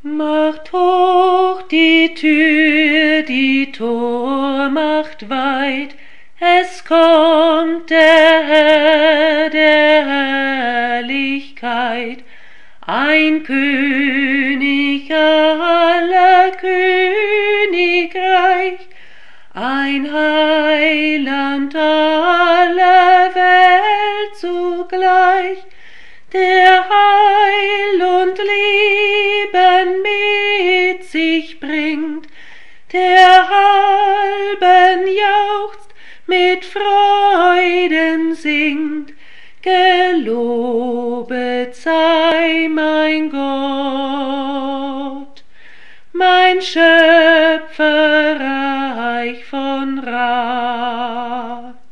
voice
a German Advent hymn written by Georg Weissel. This is the first hymn in the Lutheran hymnal in Germany.